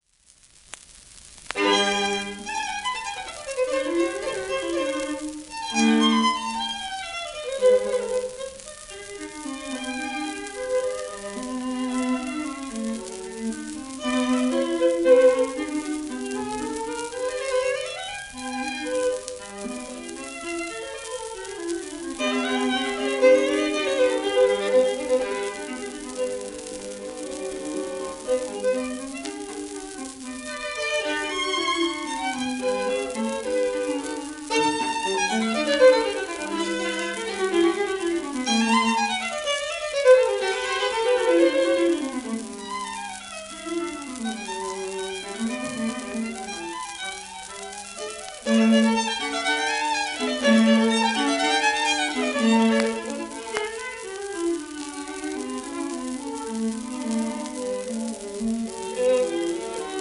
シモン・ゴールドベルク(Vn:1909-93)
盤質A- *軽度盤反り、小キズ(２面目溝白化一本分ノイズあり)
1949年録音